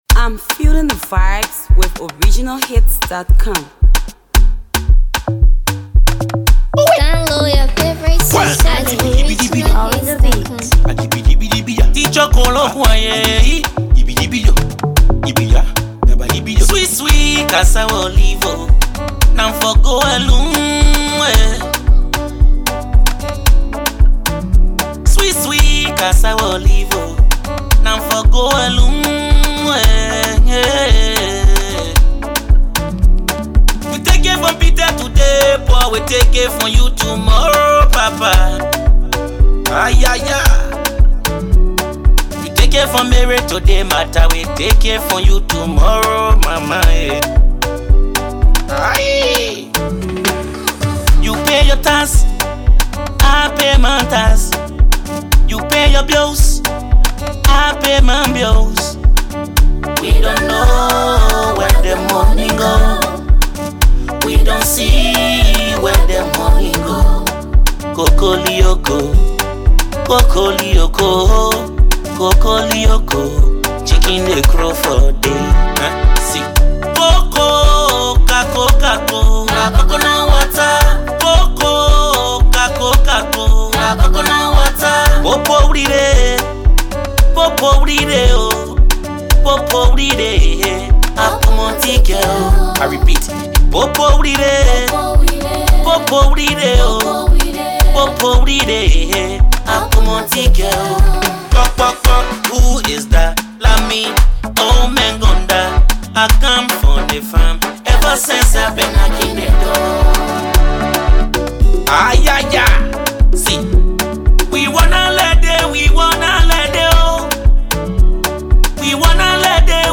it’s filled with kolopop genres